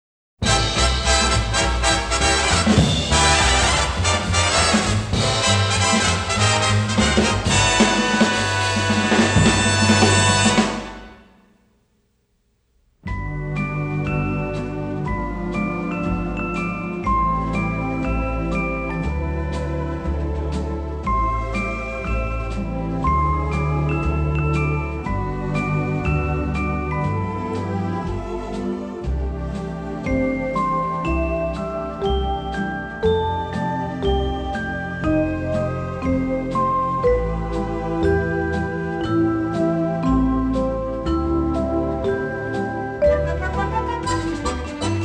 jazz and mood cues
shimmering with color, charm and melody